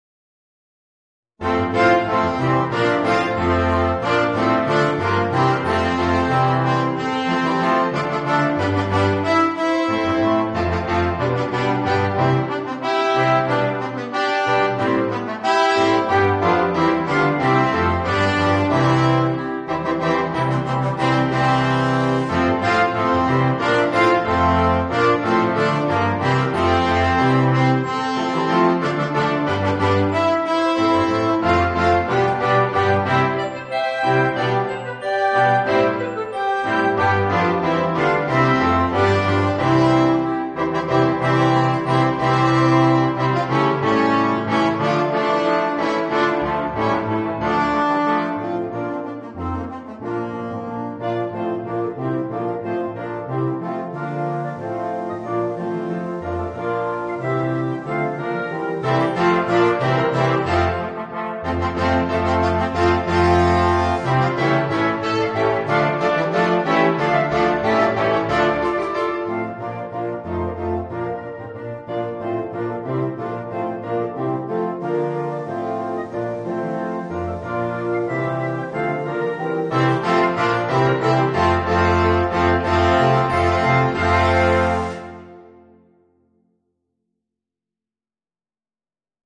Voicing: 8 - Part Ensemble